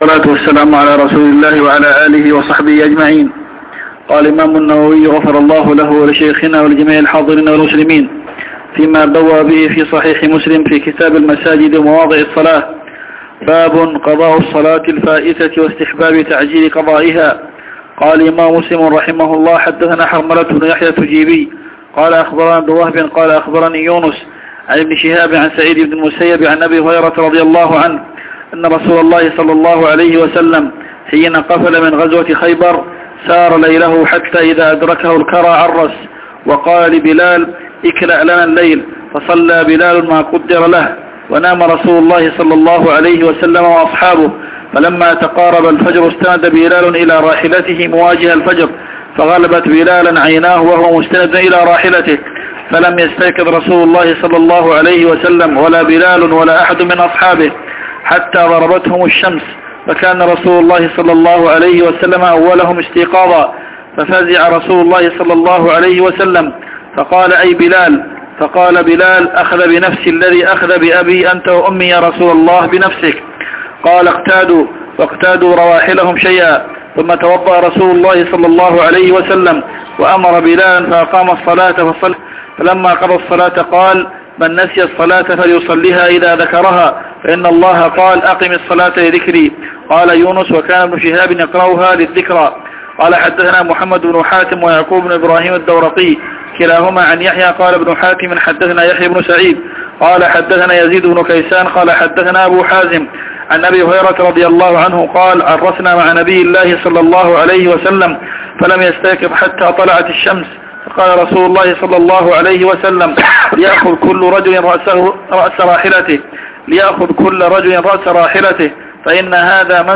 تسجيلات لدروس كتاب المساجد ومواضع الصلاة صحيح مسلم